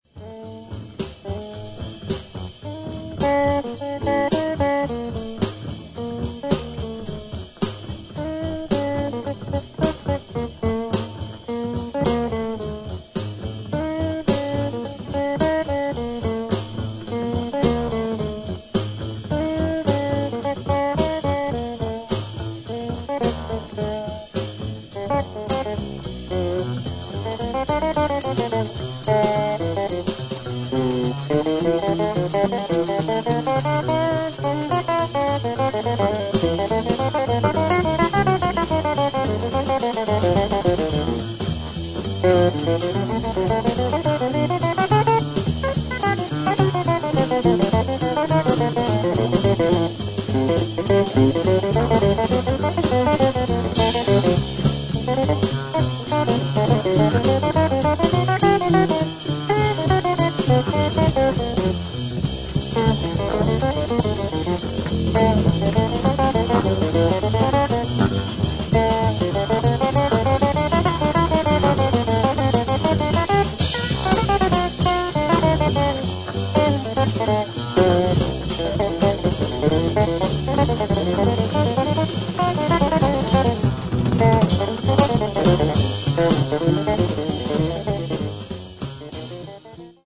アメリカン・ルーツをベースにしながらも、中東的なフレーズが印象的な楽曲など、自由度の高いセッション・フリージャズ傑作。